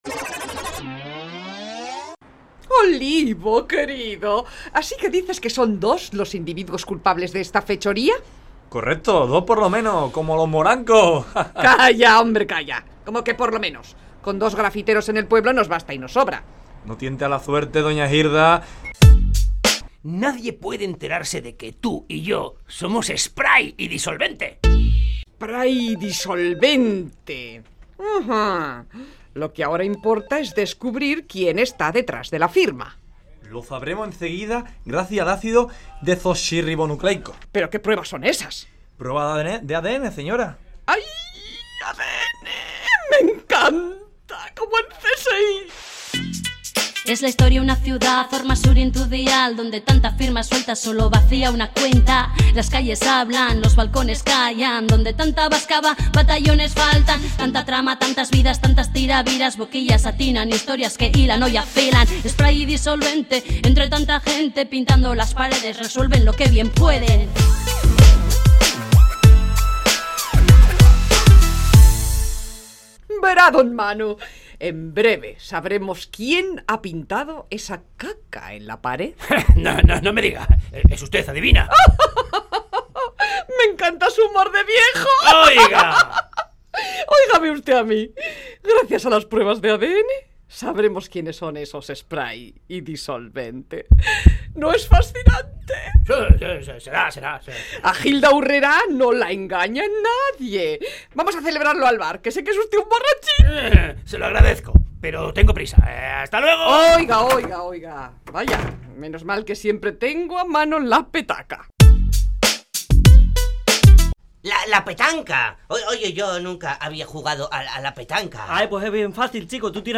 Entrega número 24 de la Radio-Ficción “Spray & Disolvente”